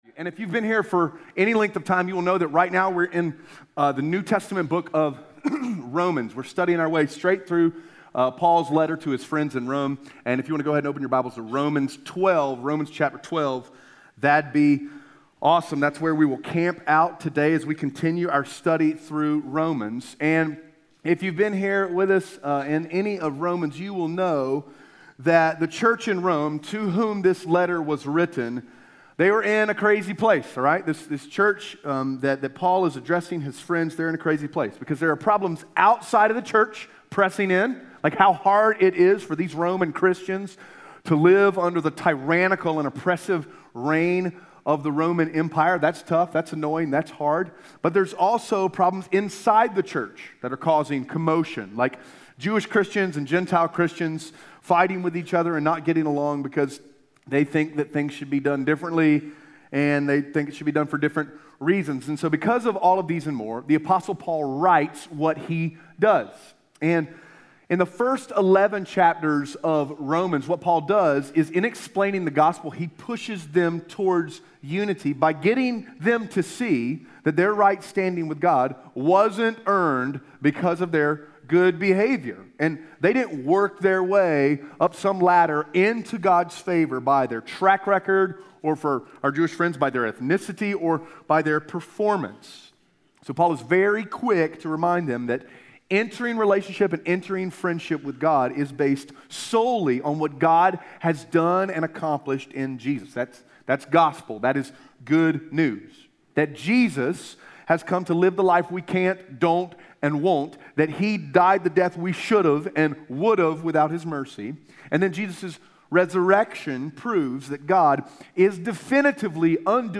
Romans 12:3-8 Audio Sermon Notes (PDF) Ask a Question Have you ever paid close attention to how football actually works?